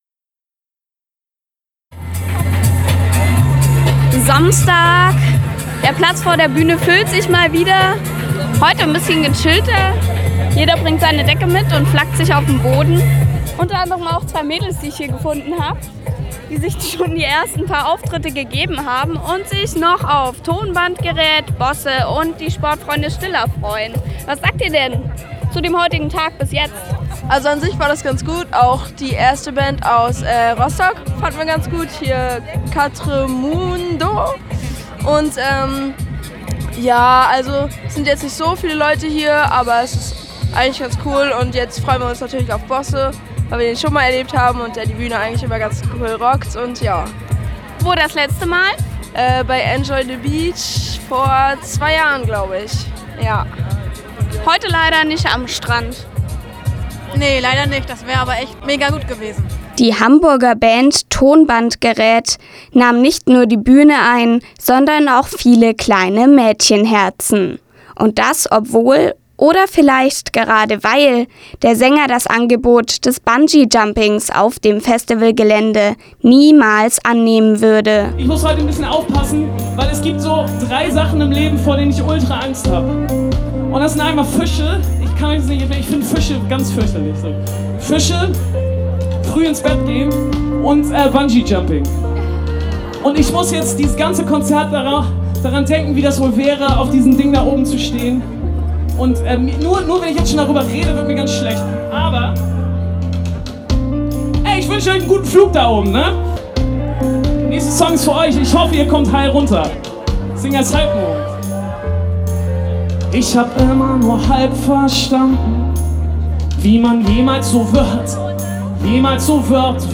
Am Freitag und am Samstag war was los im IGA Park – nämlich das Festibval Rostock Rockt mit lokalen Größen und Chartstürmern.